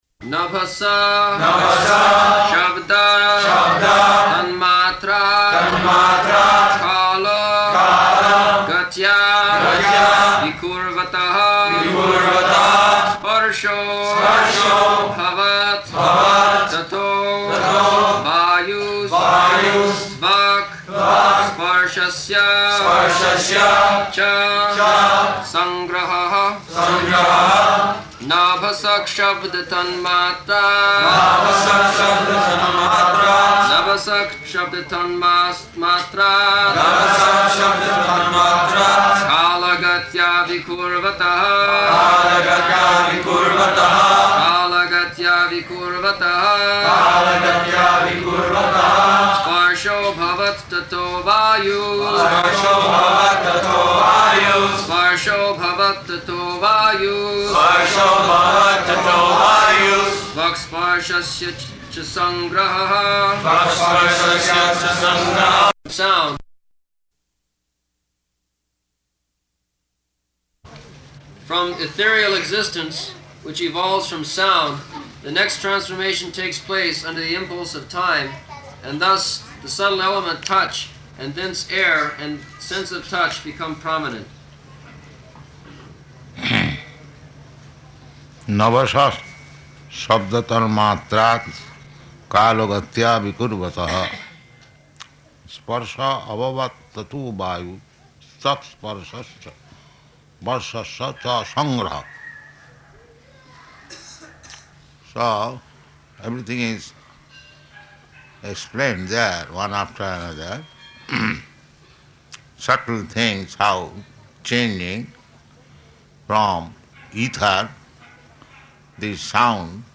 -- Type: Srimad-Bhagavatam Dated: January 12th 1975 Location: Bombay Audio file
[devotees repeat] nabhasaḥ śabda-tanmātrāt kāla-gatyā vikurvataḥ sparśo 'bhavat tato vāyus tvak sparśasya ca saṅgrahaḥ [ SB 3.26.35 ] Sound.